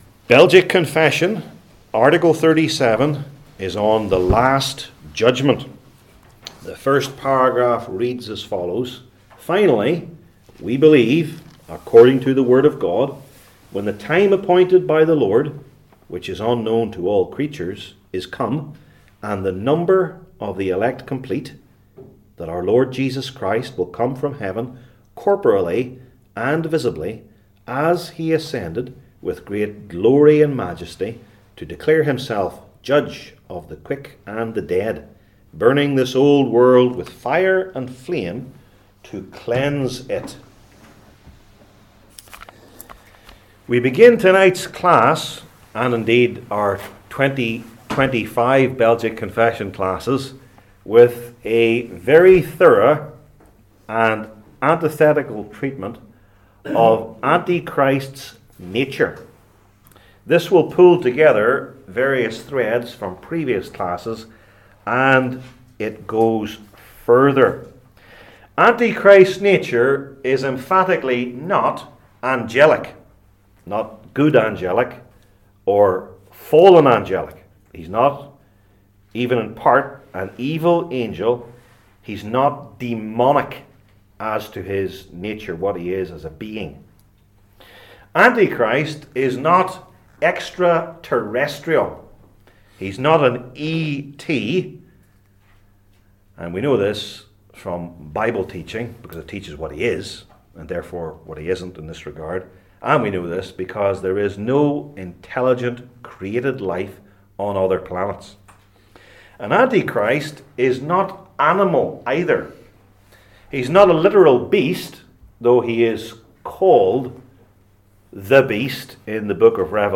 Passage: II Thessalonians 2:1-15 Service Type: Belgic Confession Classes